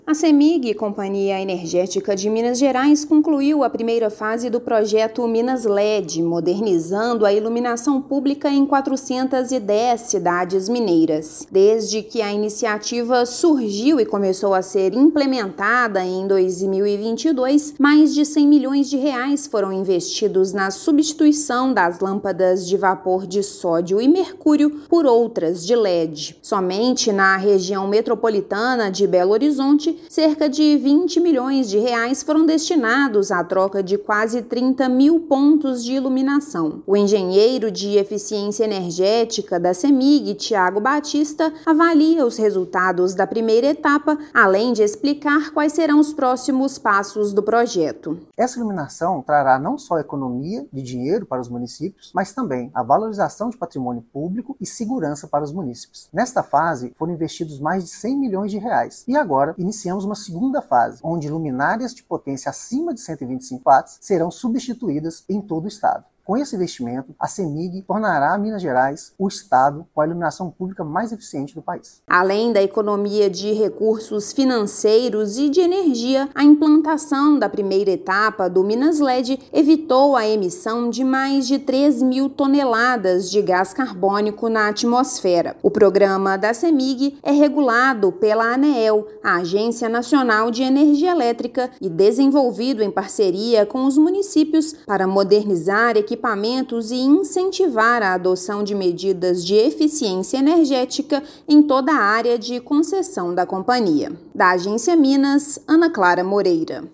Companhia modernizou e substituiu mais de 130 mil pontos de iluminação no estado. Ouça matéria de rádio.